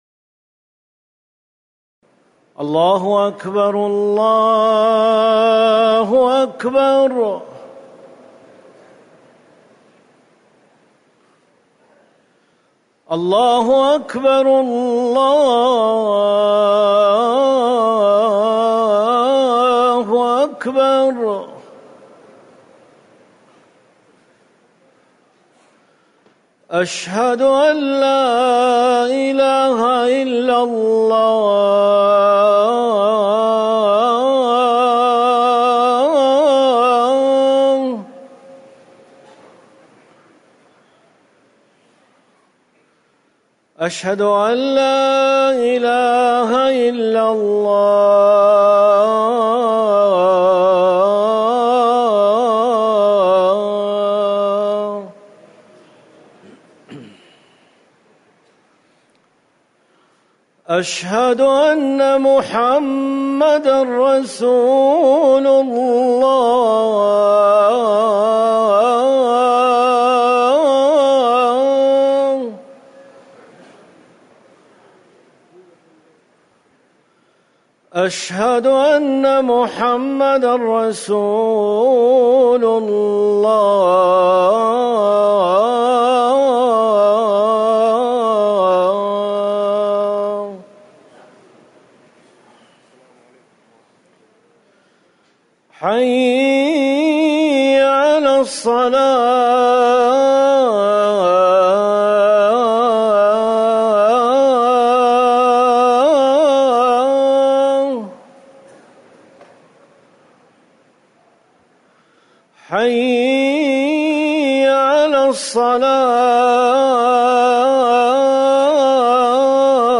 أذان الجمعة الأول - الموقع الرسمي لرئاسة الشؤون الدينية بالمسجد النبوي والمسجد الحرام
تاريخ النشر ١٤ محرم ١٤٤١ هـ المكان: المسجد النبوي الشيخ